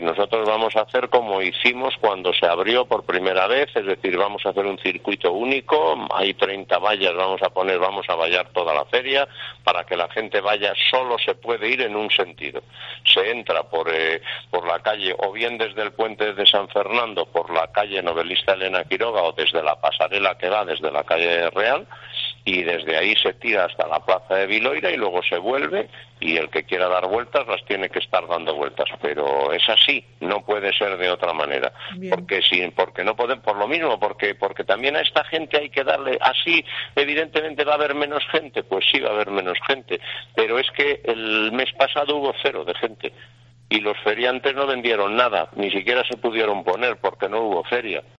Declaraciones de Alfredo García, alcalde de O Barco, sobre la feria de Viloira